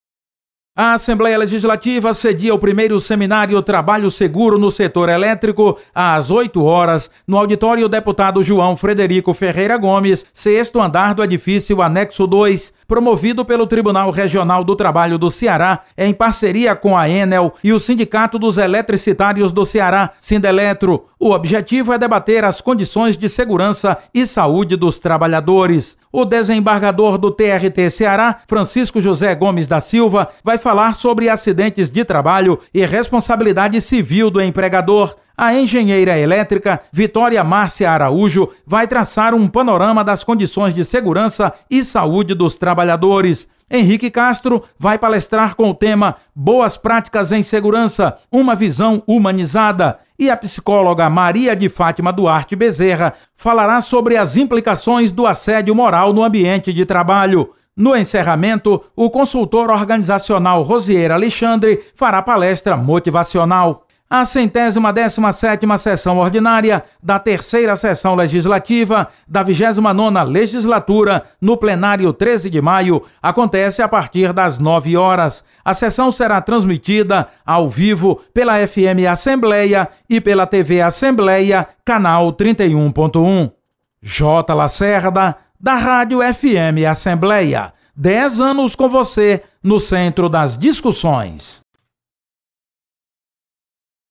Acompanhe as atividades desta sexta na Assembleia Legislativa com o repórter